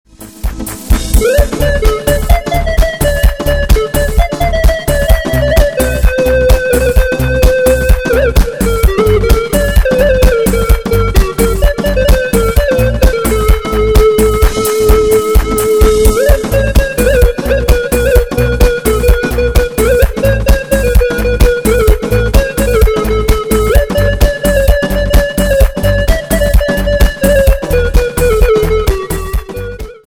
кавказские , без слов